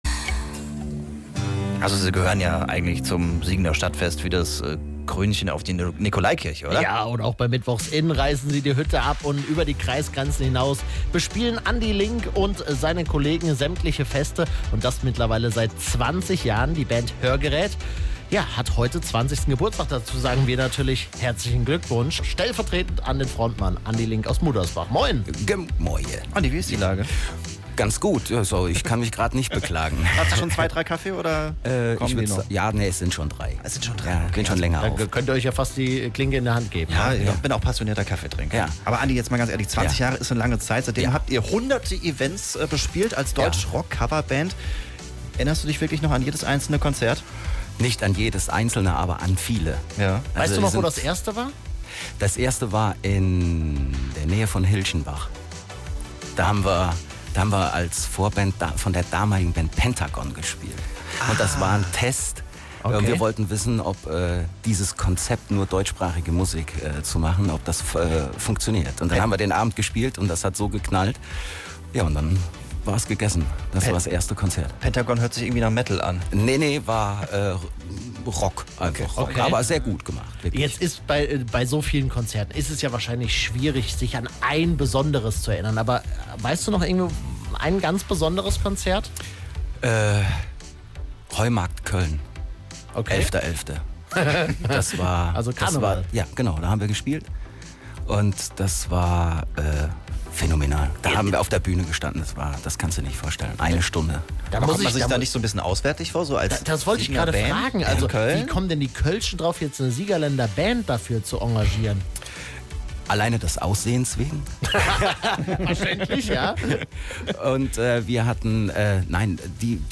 20 Jahre Hörgerät - Das Jubiläumsinterview